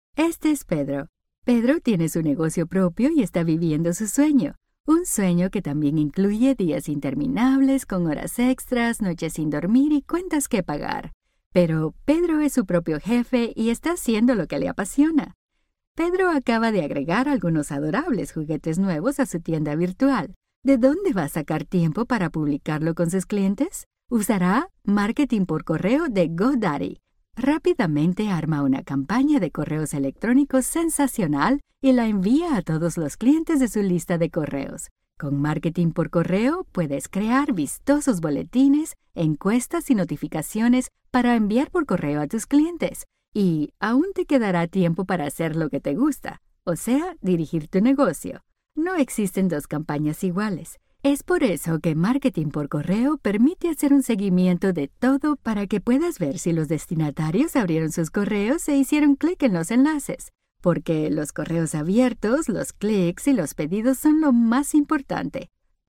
Neutral Latin American Spanish female voice.
Sprechprobe: eLearning (Muttersprache):